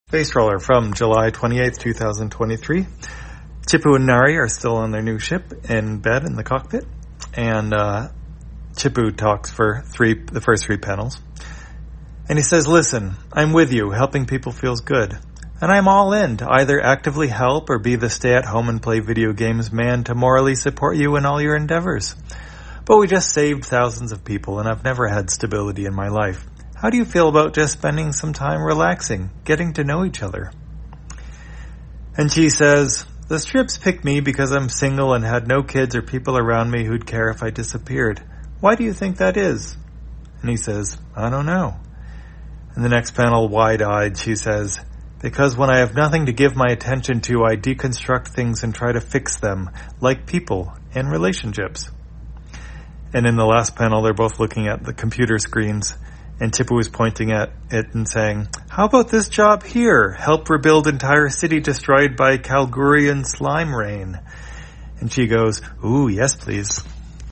Spacetrawler, audio version For the blind or visually impaired, July 28, 2023.